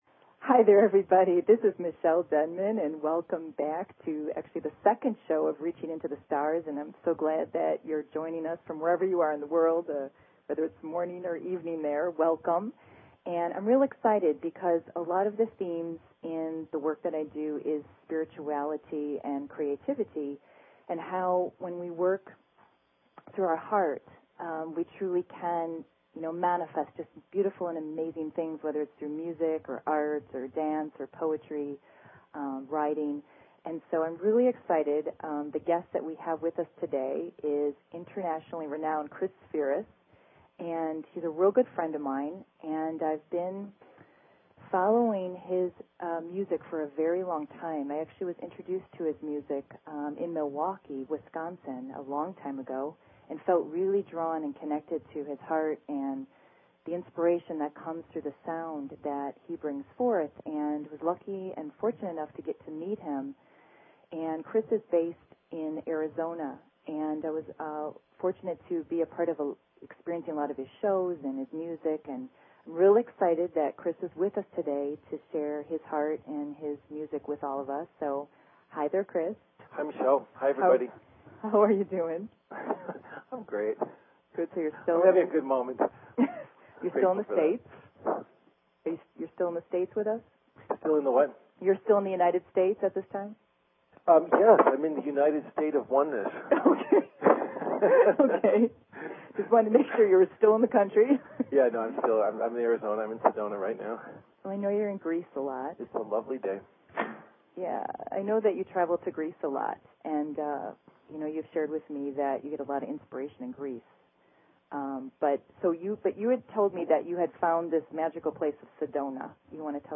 Talk Show Episode, Audio Podcast, Reaching_into_the_Stars and Courtesy of BBS Radio on , show guests , about , categorized as
Guest: Chris Spheeris - a famous musician